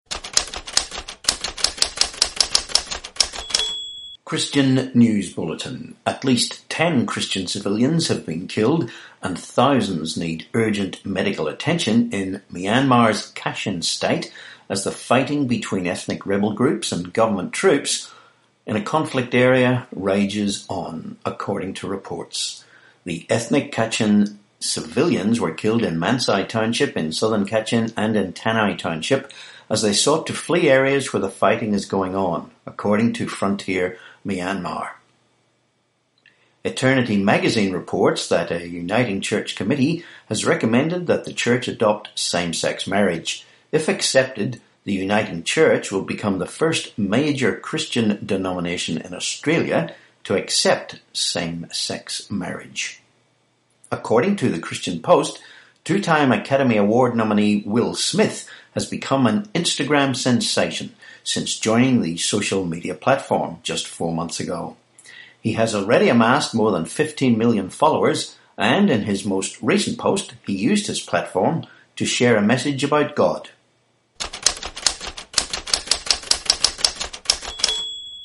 6May18 Christian News Bulletin